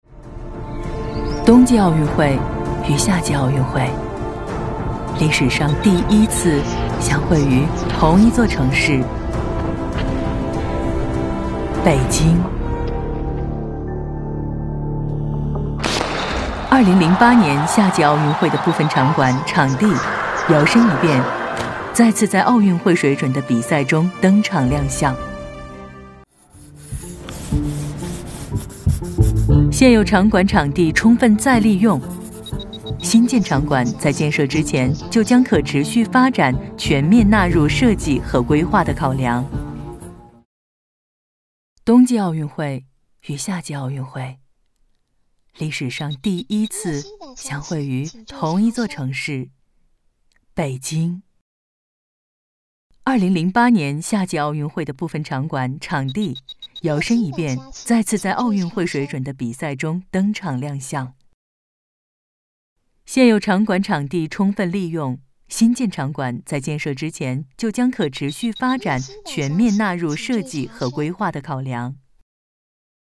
女102-宣传片-一切刚刚开始（冬奥遗产系列）
女102专题广告 v102
女102-宣传片-一切刚刚开始-冬奥遗产系列-.mp3